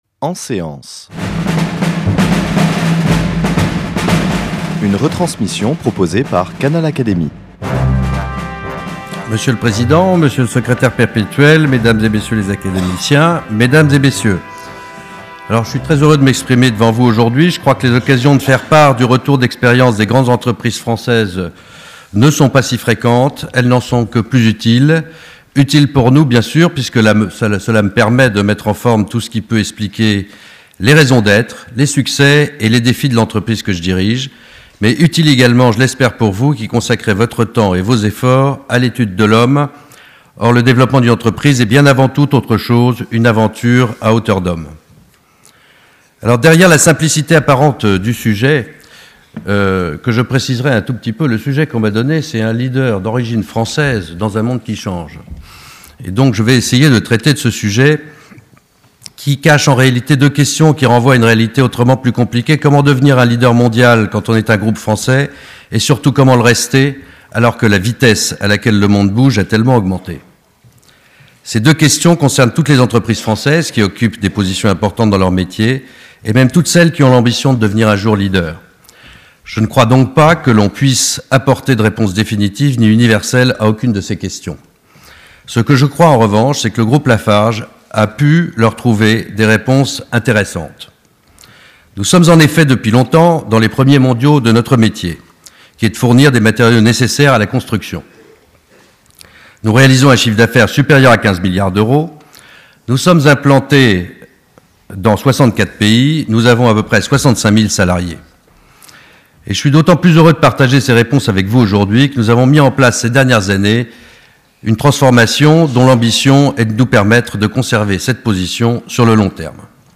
La séance est suivie des débats, contenu exclusif réservé au membres du Club Canal Académie.